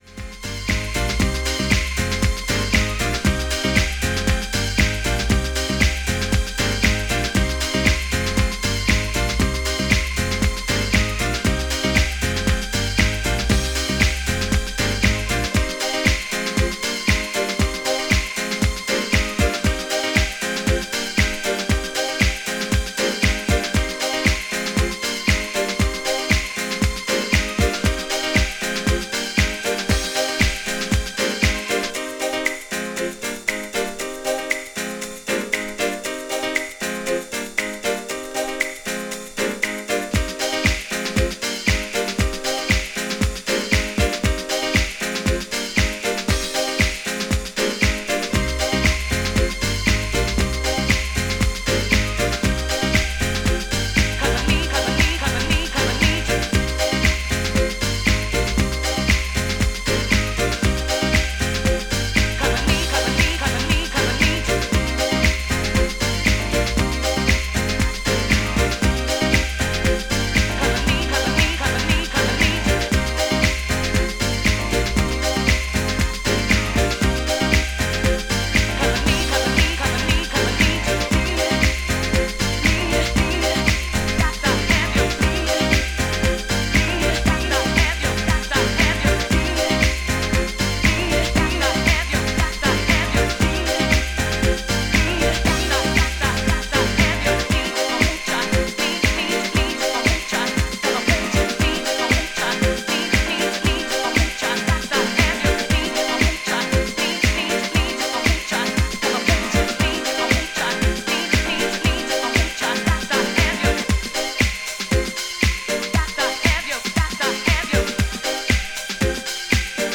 STYLE House